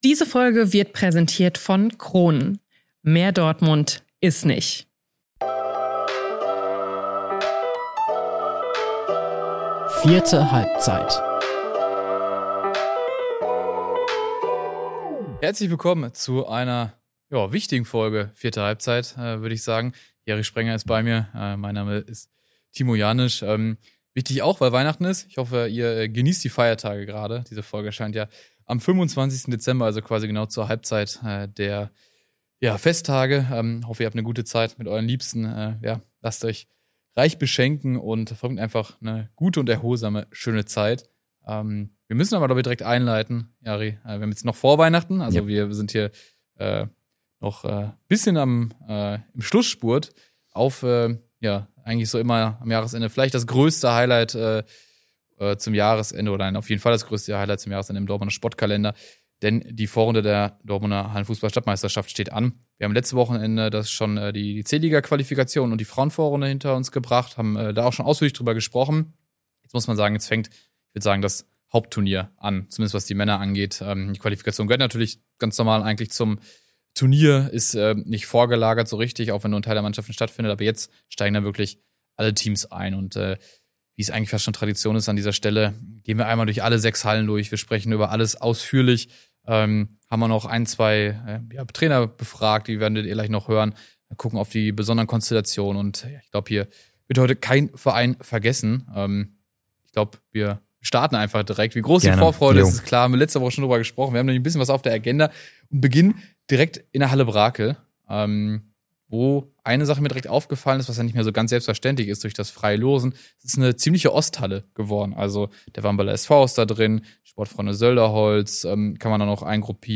Außerdem: Zwei Trainer kommen selbst zu Wort.